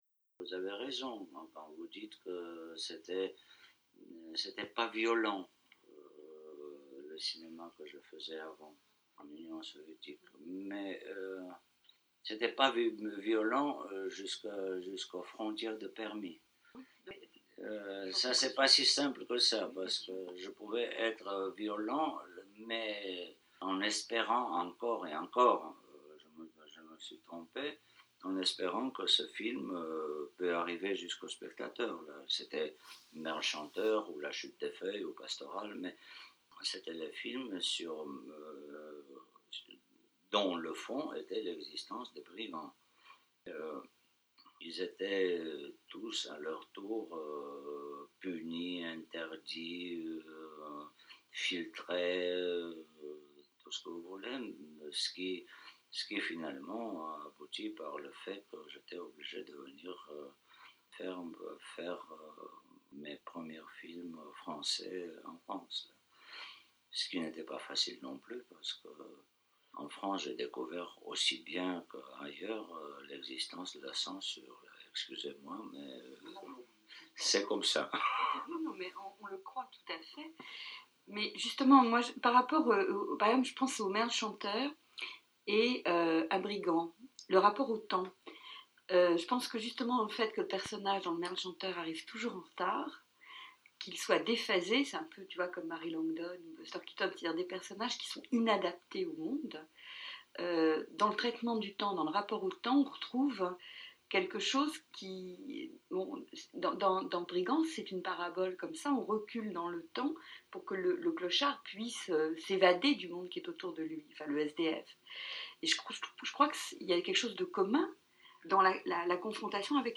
Entretien réalisé en 1996 lors de la sortie de " Brigands chapitre VII" que vous pourrez voir le 16 juin dans la rétrospective de la Cinémathèque, une fable féroce et burlesque où bourreaux du Moyen- Âge, Commissaires du peuple tortionnaires en Union soviétique et mafias en Géorgie mènent la danse d’un carnaval effréné, macabre mais joyeux qui contamine le monde !